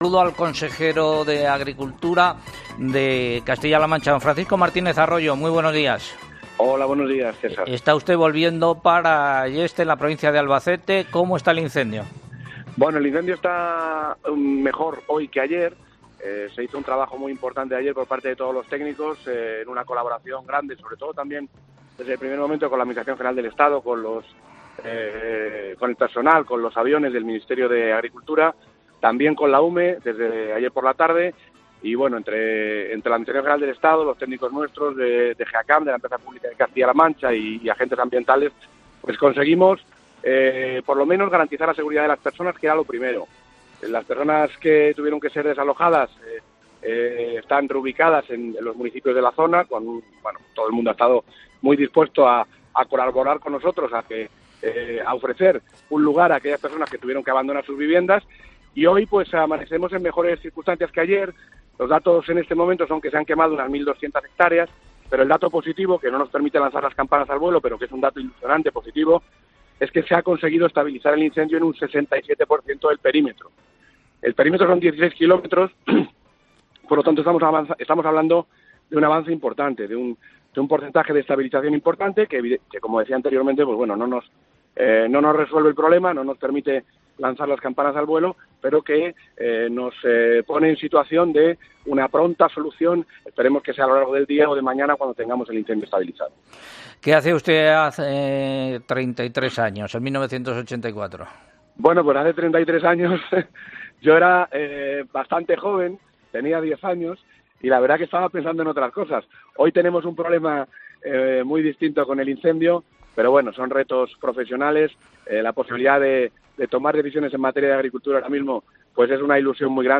“Hemos podido garantizar la seguridad de las personas”, consejero de Agricultura de Castilla-La Mancha, Francisco Martínez Arroyo